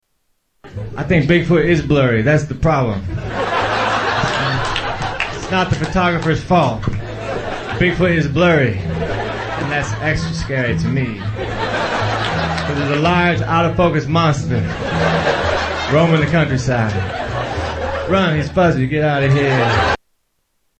Tags: Comedians Mitch Hedberg Sounds Mitchell Lee Hedberg Mitch Hedberg Clips Stand-up Comedian